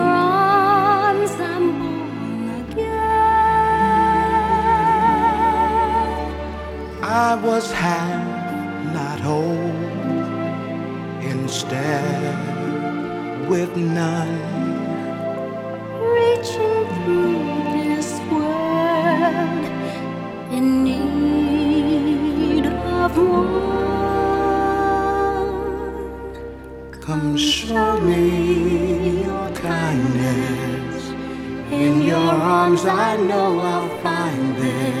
Жанр: R&B / Соул
R&B, Soul